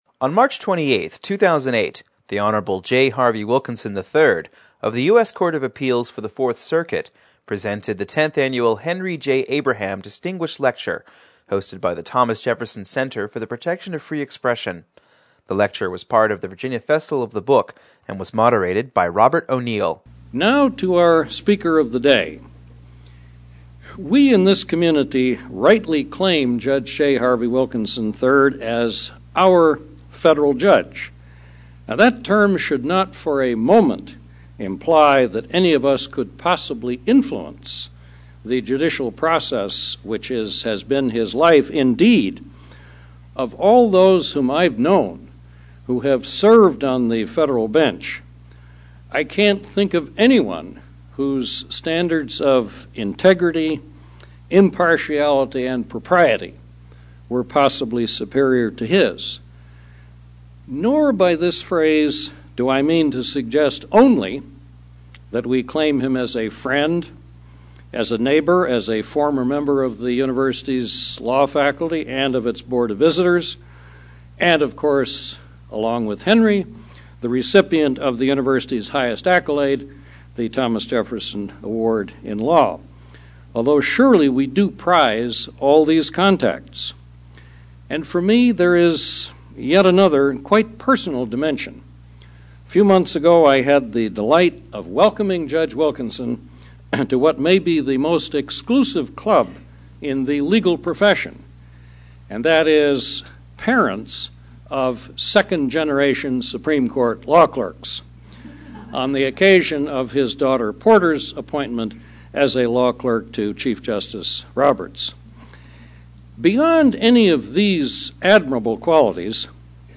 On March 28, 2008, the Hon. J. Harvie Wilkinson, III, of the U.S. Court of Appeals for the Fourth Circuit presented the Tenth Annual Henry J. Abraham Distinguished Lecture Series, hosted by the Thomas Jefferson Center for the Protection of Free Expression.